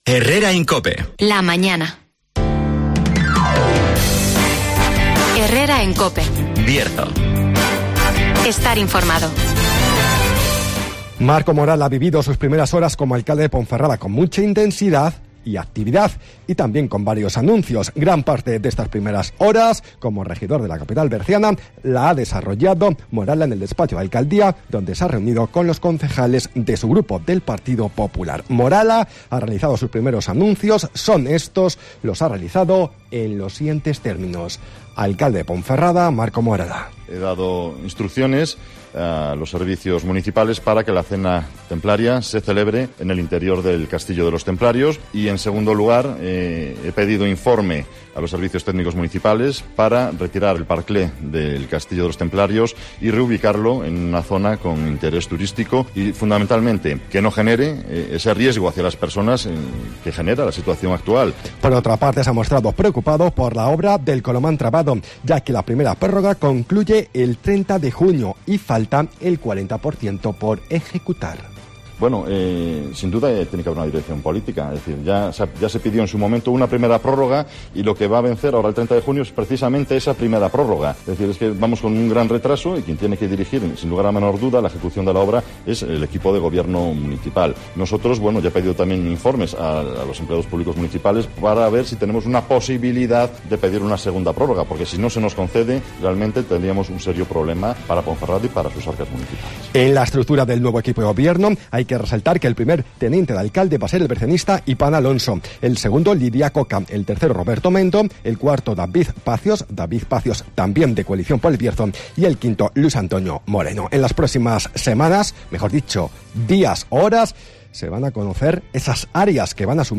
-Resumen de las noticias -El tiempo -Agenda -Los 10 km solidarios de Proyecto Hombre 2023 ya calientan motores (Entrevista